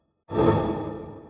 Trimmed-Holy Buffs